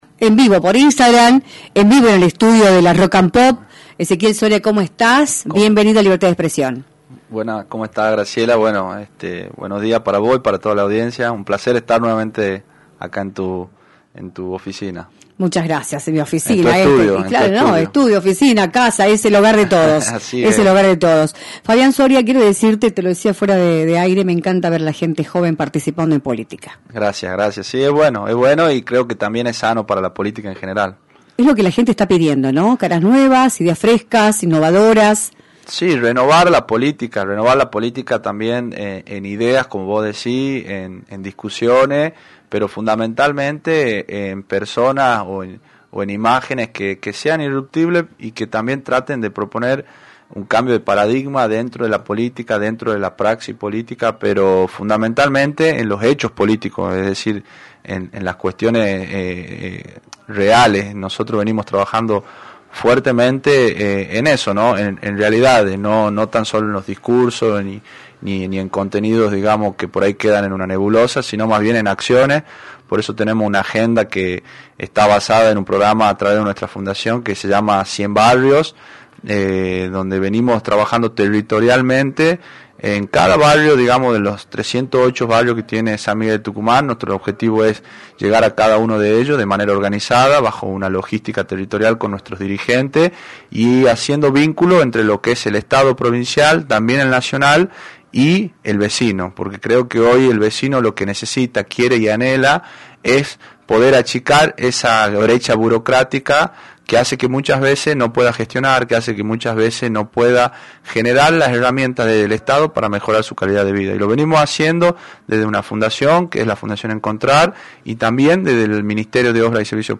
visitó los estudios de “Libertad de Expresión” por la 106.9
entrevista